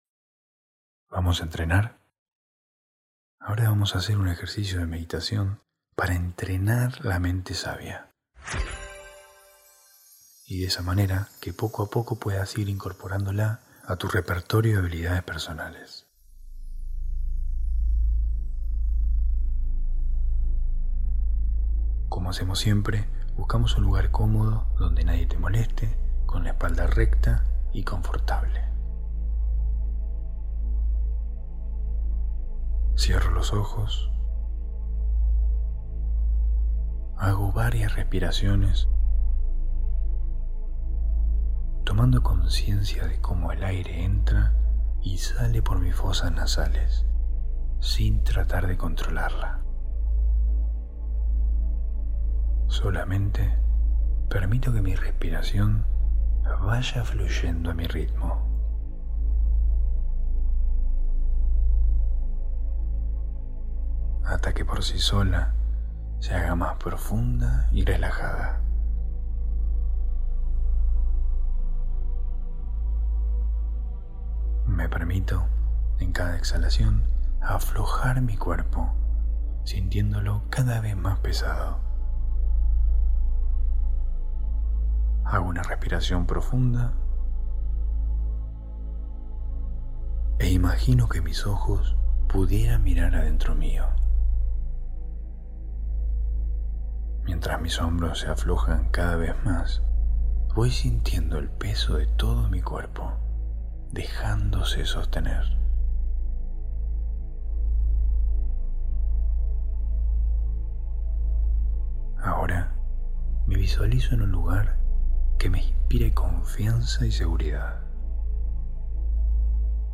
Meditación para entrenar la Mente Sabia ✨